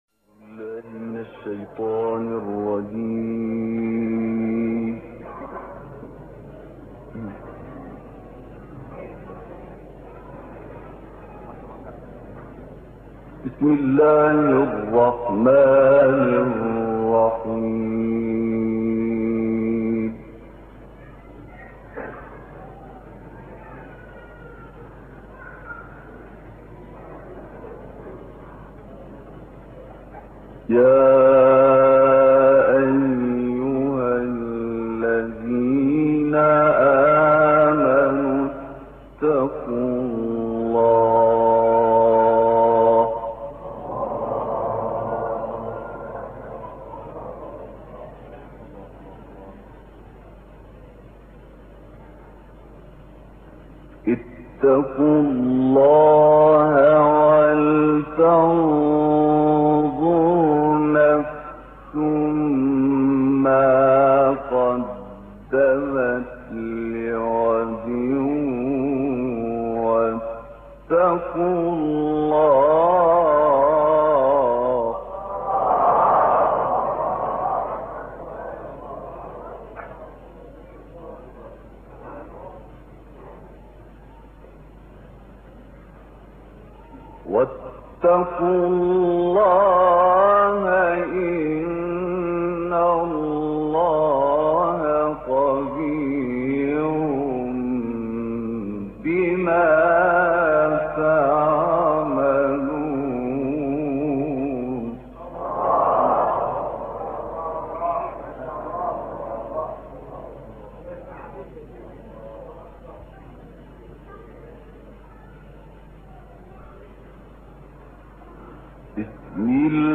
تلاوتی زیبا از سوره مبارکه حشر با صدای دلنشین استاد احمد بسیونی + صوت
کانون خبرنگاران نبأ: استاد احمد بسیونی یکی از قاریان بزرگ جهان اسلام است که در سال ۱۳۳۰ در کشور مصر متولد شد و هم‌اکنون یکی از اساتید دانشگاه الازهر مصر است.